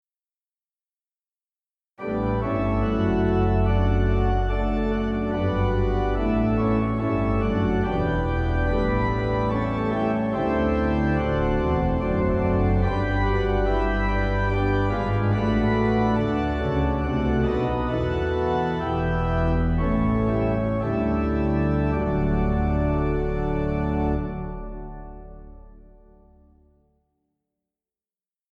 Hymn Harmonizations